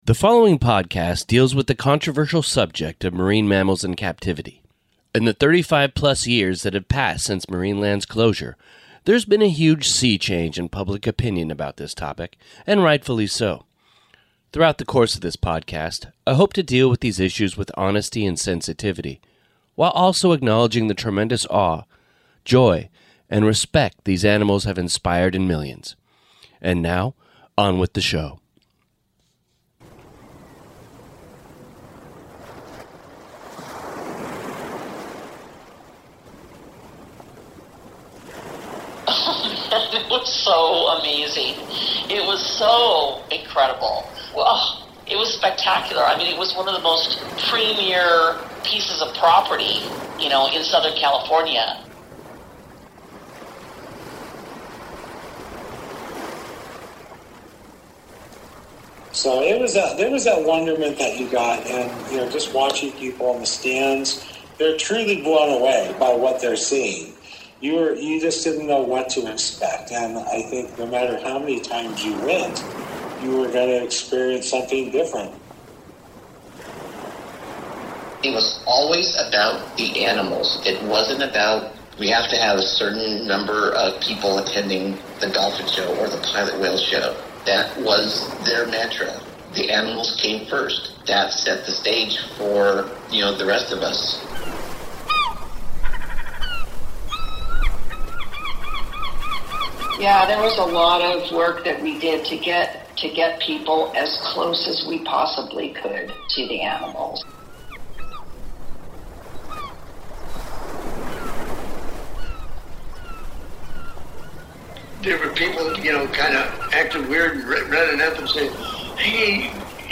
We’ll also talk to some former park employees about the dangers of working alongside a flock of fearless seagulls at the park and why the location on the cliffs of Palos Verdes was so incredible.